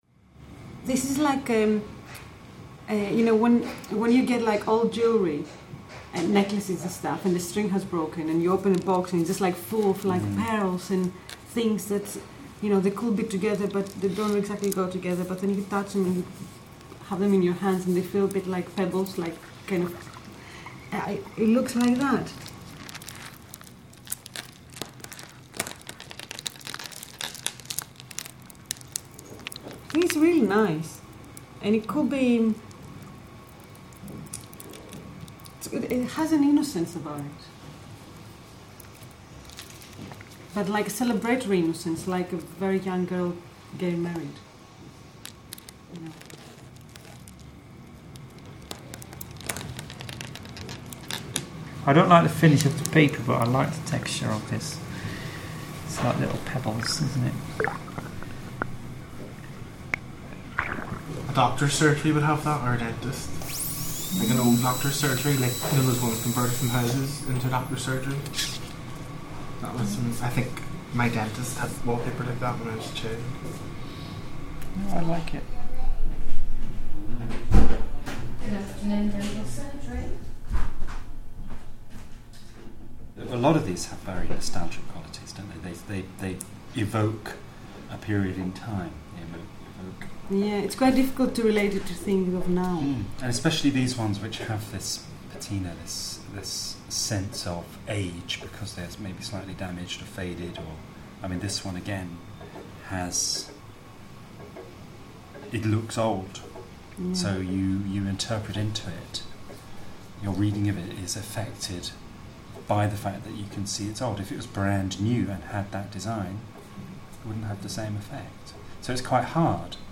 BADDA 4386 - no post-production on voices
Field recordings at the BDA Museum, Barnet Museum, MoDA and the beach have been layered to create a kind of sonic or audible reflection of these ideas; a sonic equivalent to the visual, paper design.